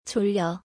チョリョ